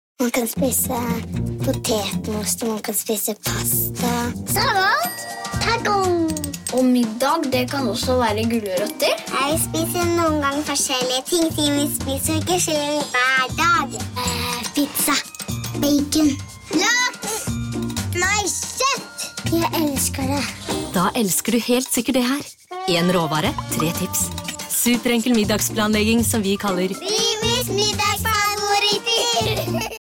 Female
Friendly, Confident, Character, Corporate, Energetic, Natural, Warm, Engaging
Microphone: SM Pro Audio MC01